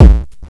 KICK - SHORT.wav